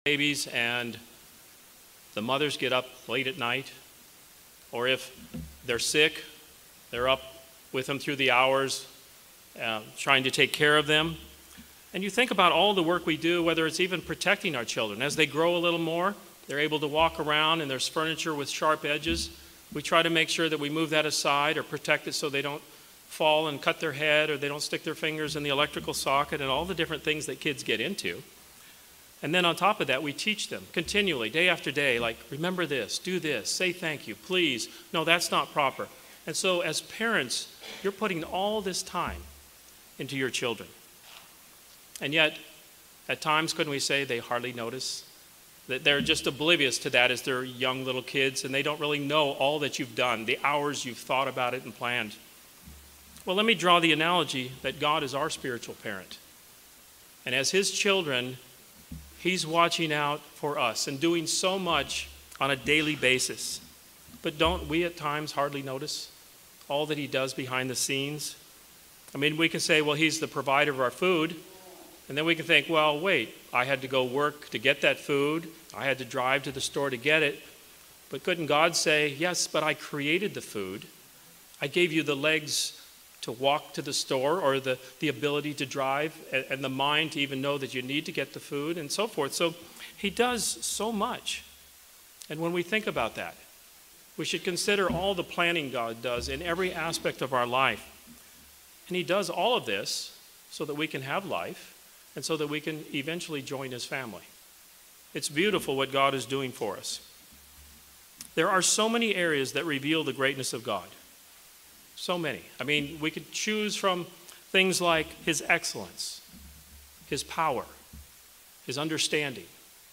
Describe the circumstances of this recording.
Given in Orlando, FL